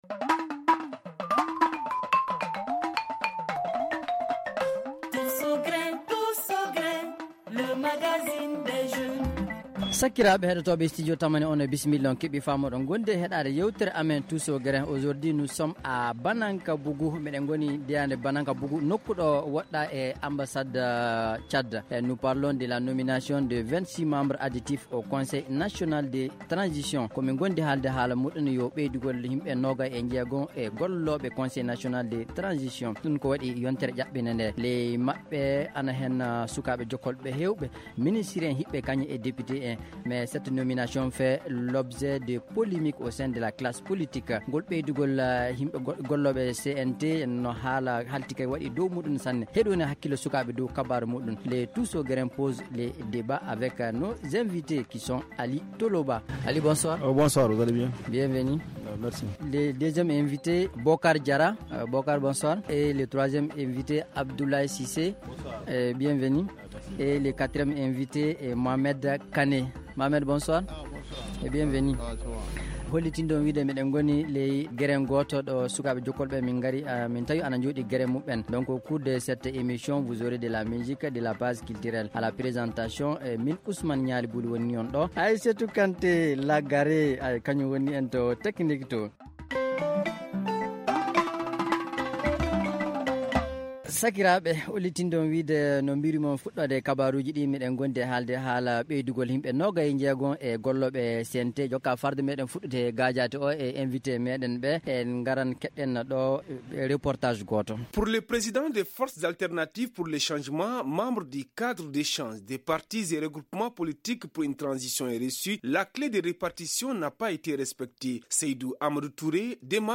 Mais d’autres jeunes aussi estiment qu’on pouvait se passer de cette augmentation du nombre de conseillers. L’équipe du Tous au Grin du programme jeune de Studio Tamani s’est rendue dans un grin à Banankabougou en commune VI du district de Bamako pour en débattre.